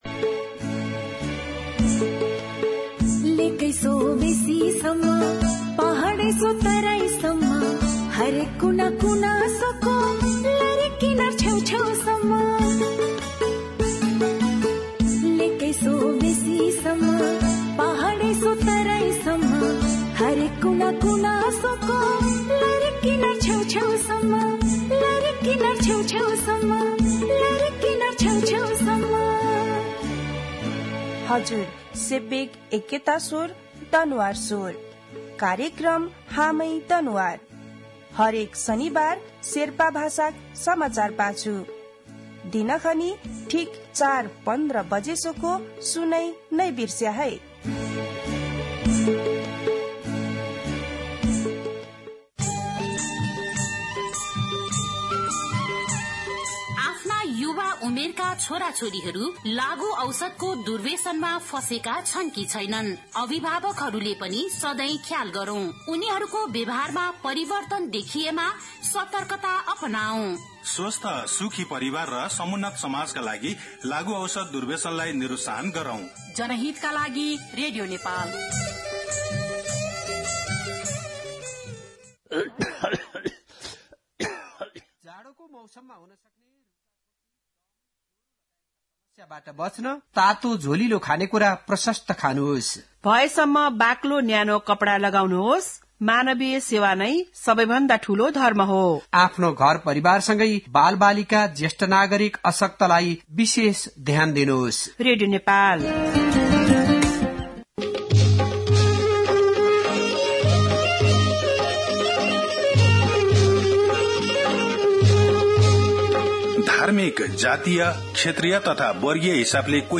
दनुवार भाषामा समाचार : १३ माघ , २०८१
Danuwar-News-2.mp3